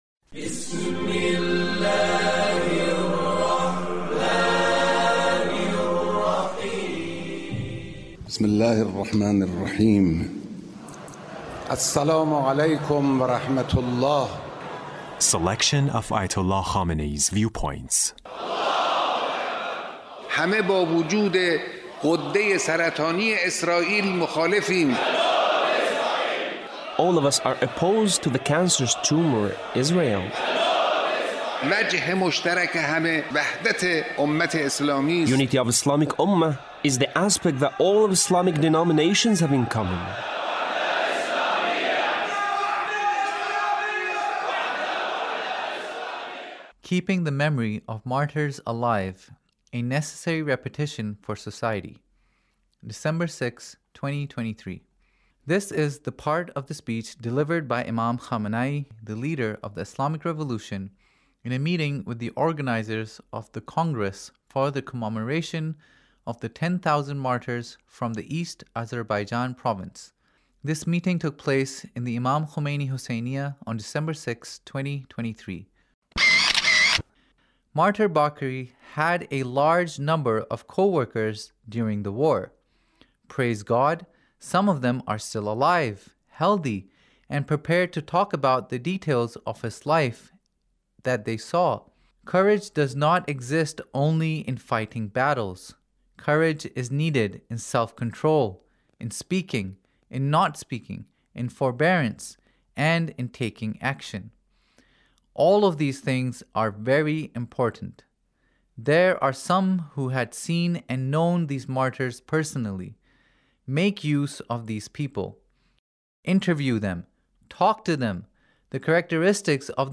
Leader's Speech (1874)
Leader's Speech about , in a meeting with the organizers of the Congress for the Commemoration of the 10,000 Martyrs from the East Azerbaijan Province